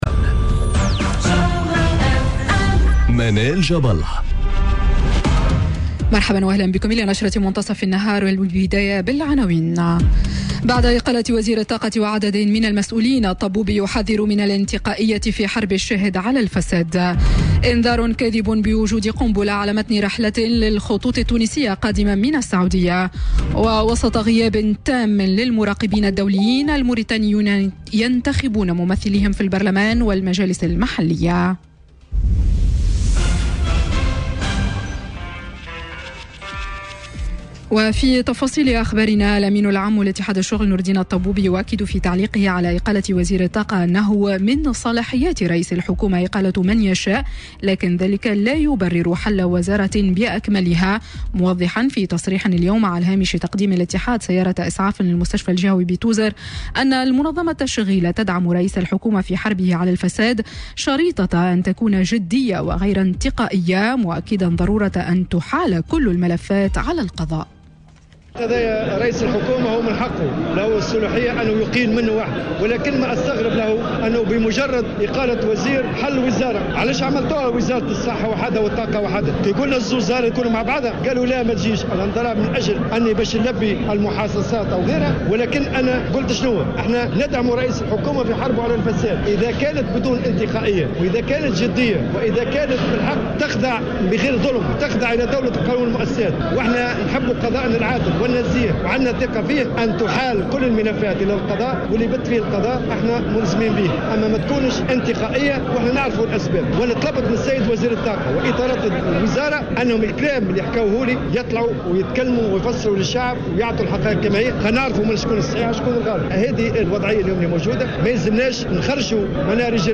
Journal Info 12h00 du samedi 01 septembre 2018